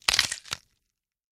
Звук где у мумии хрустнула шея когда она ее вправляла